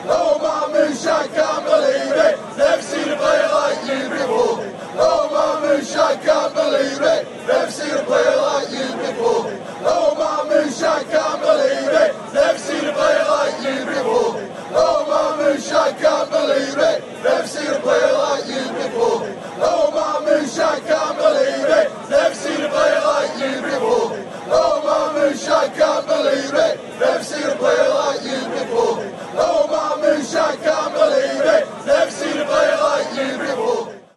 Raising the rafters at the City of Manchester stadium!